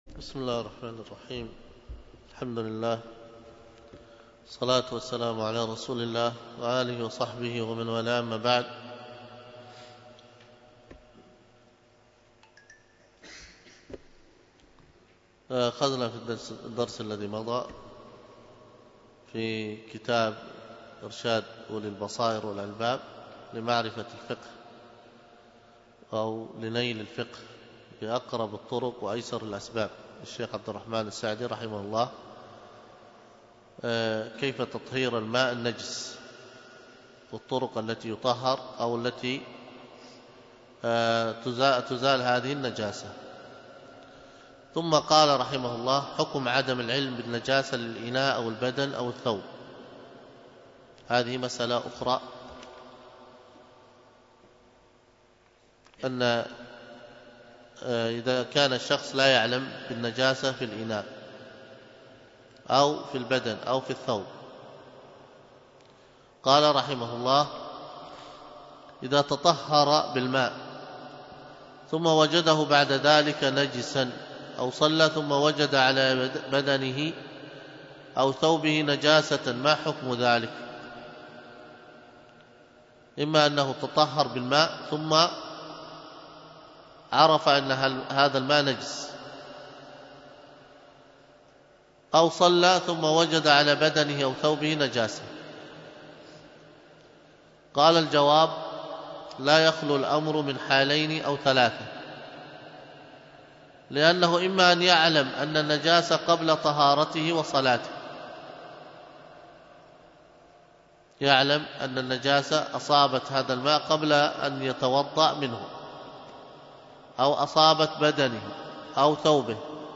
تعليق - الرد على الأخنائي 3 تحميل الدرس في تعليق - الرد على الأخنائي 3، الدرس الثالث : من : (التحذير من الكلام في دين الله بغير علم ...